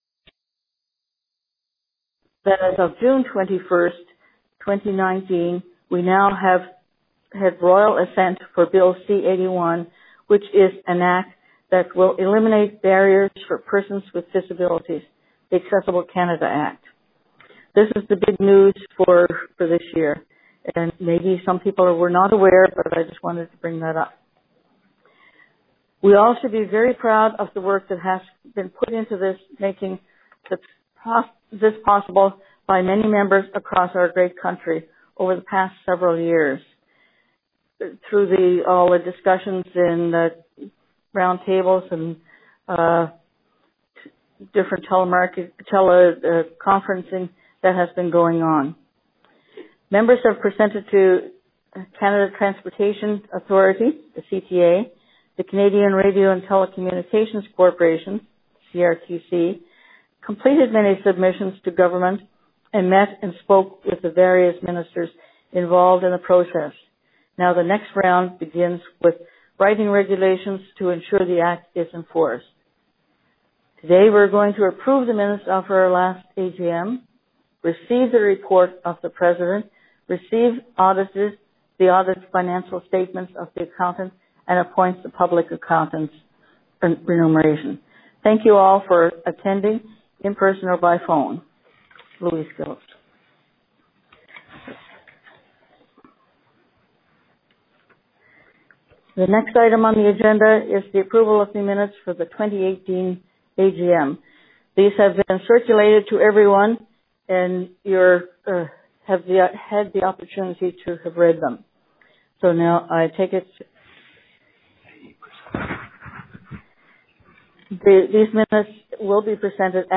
2019 AGM Audio Recording
Also listed below is a time edited recording with the silences removed.
CCB AGM - Edited.mp3